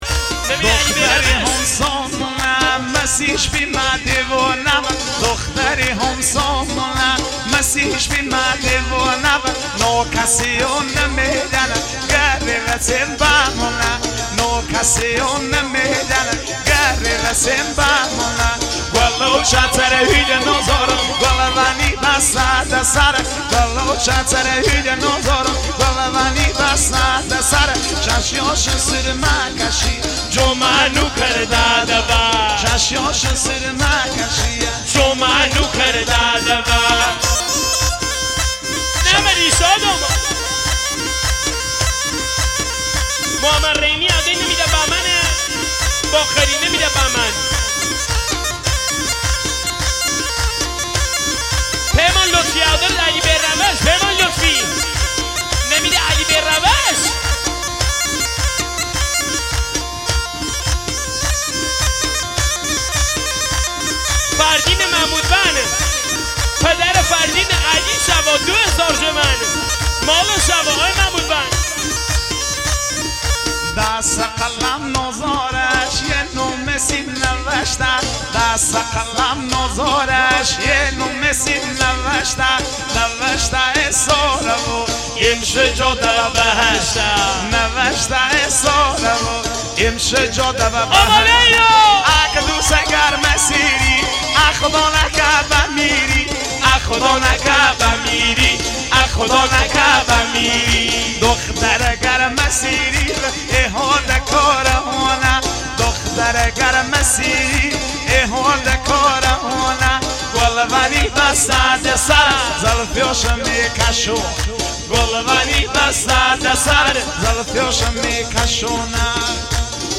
عالی هست این آهنگ لری